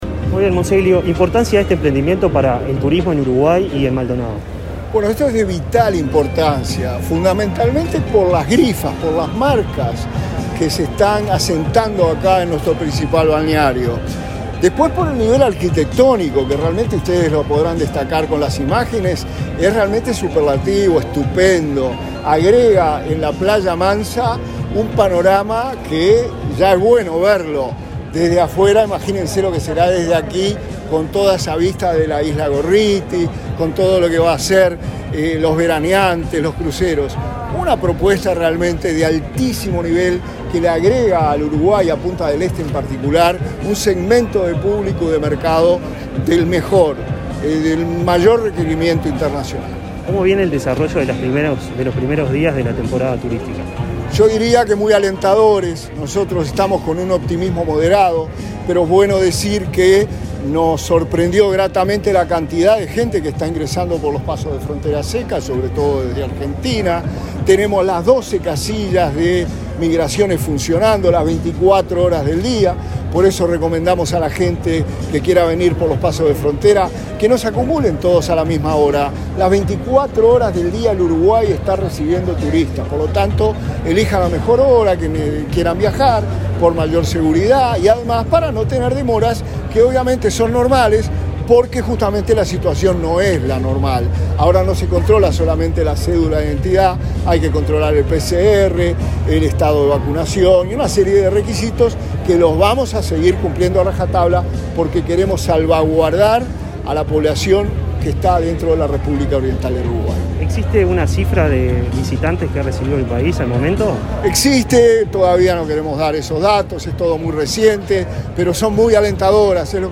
Entrevista al subsecretario de Turismo, Remo Monzeglio